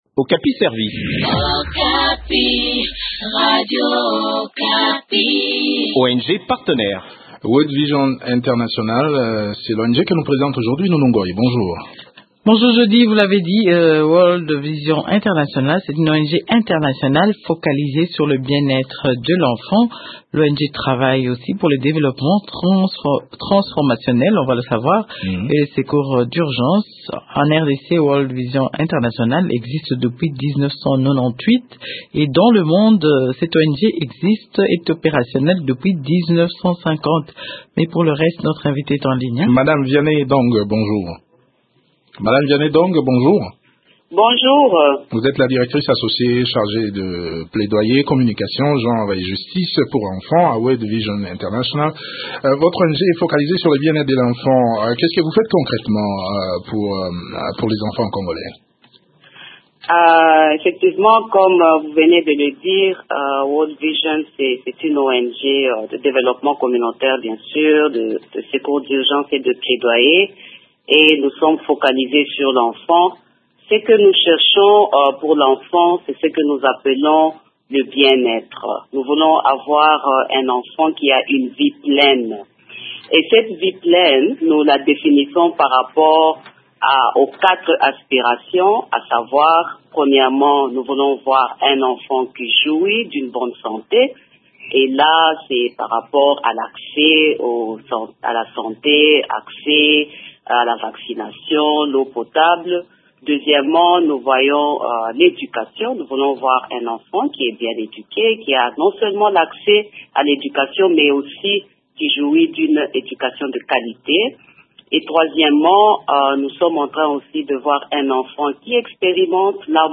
parle de leurs activités au micro